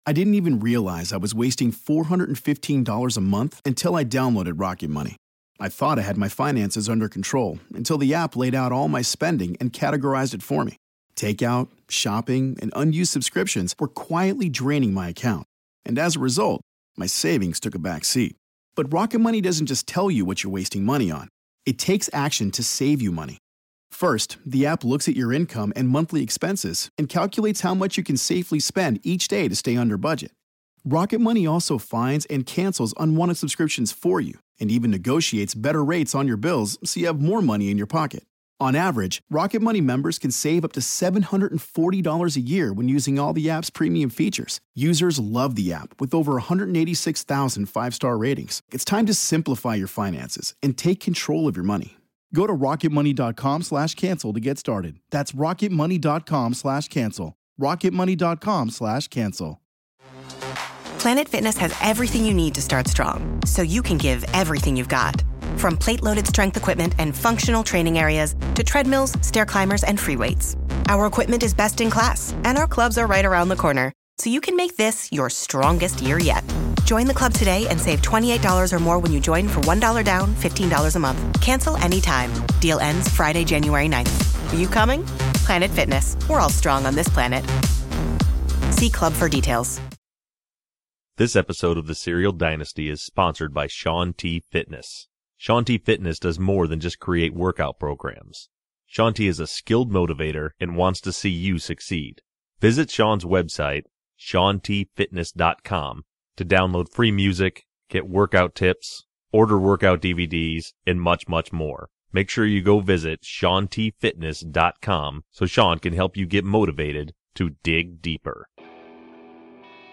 We also hear a conversation from Serial Dynasty sponsor, Shaun T.